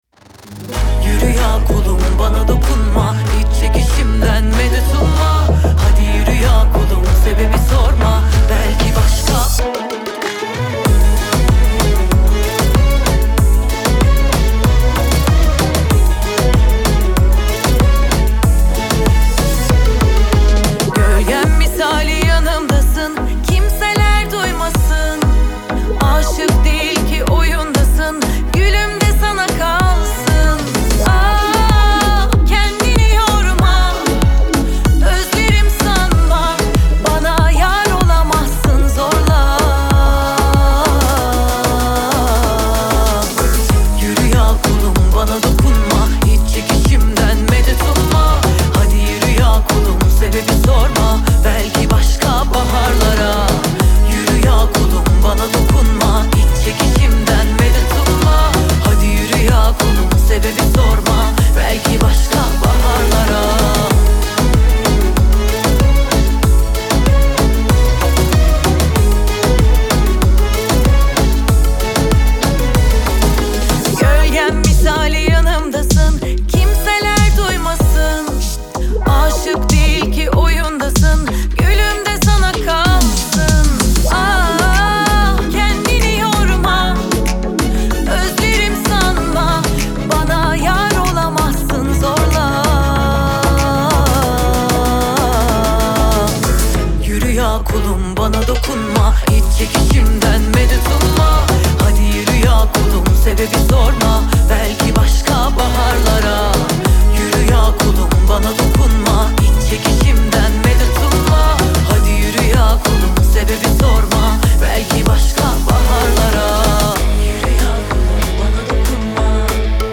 Трек размещён в разделе Турецкая музыка / Поп.